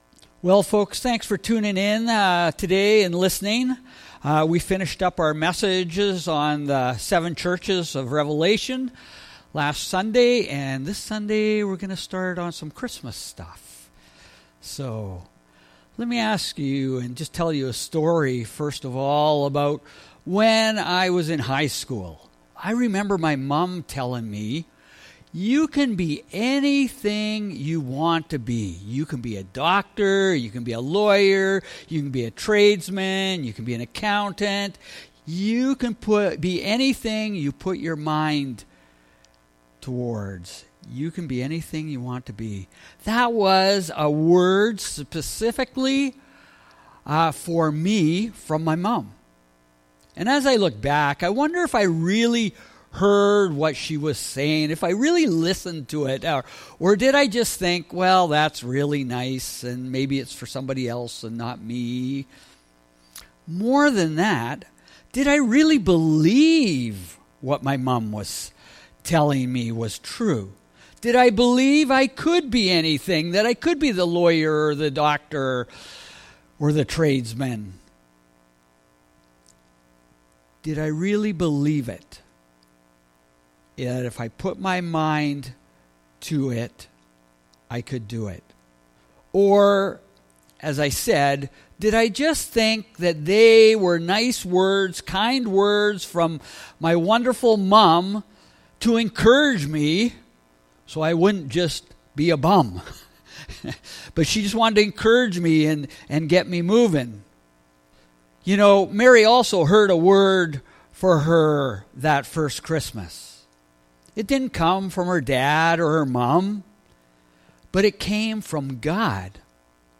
Sermons | Cross Roads Pentecostal Assembly